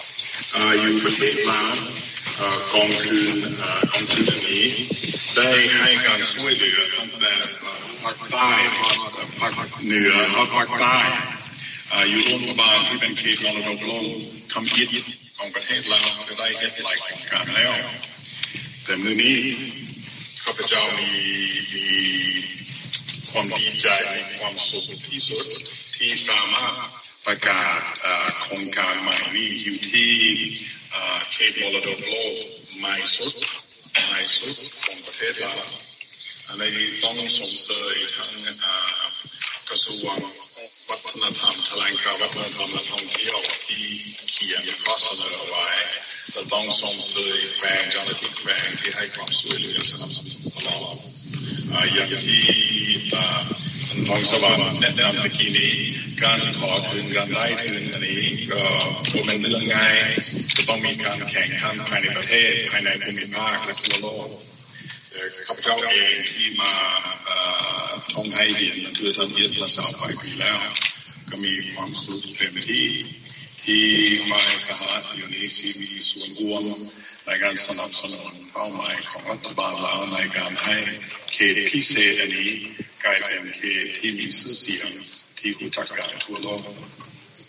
ເອກອັກຄະລັດຖະທູດສະຫະລັດ ປະຈຳ ສປປ ລາວ ທ່ານດຣ. ປີເຕີ ເຮມອນ ກ່າວປະກາດ ການໃຫ້ທຶນມູນຄ່າ 129,000 ໂດລາ